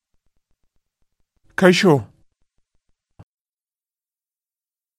Just click on the languages below to hear how to pronounce “Hello”.